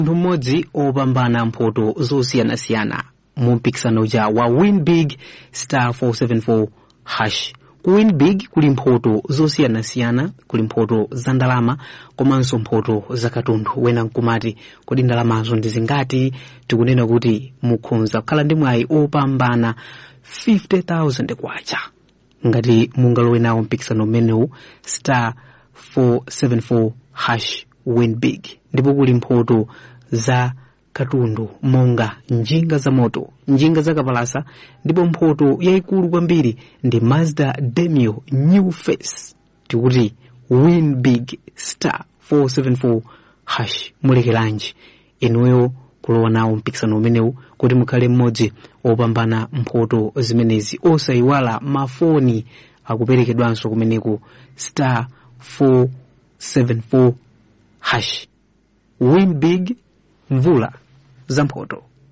Stand A Chance To Win Live Read